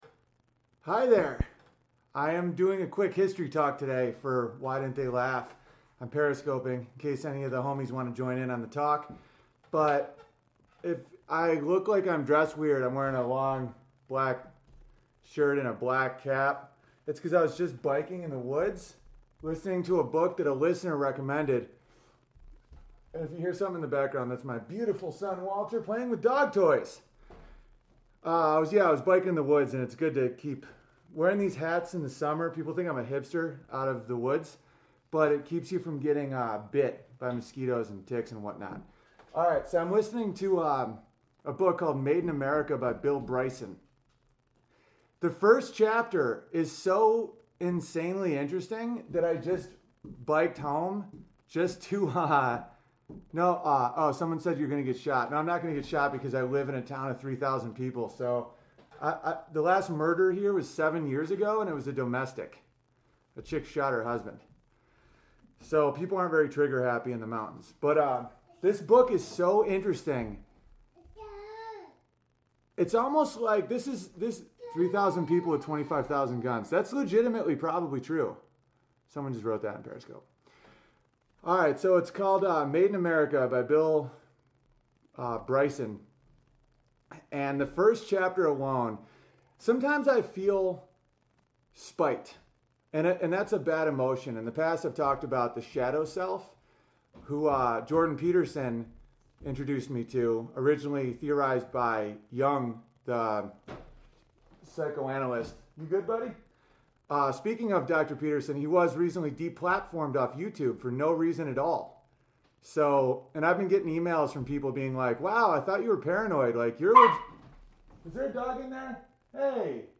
Columbus, vikings, Pilgrims, the Irish, lacrosse, Verizano, Squanto, and tons of other historical icons have really really funny histories. I go on some tangens, play a little music and run out of card space on my zoom right as I was closing out.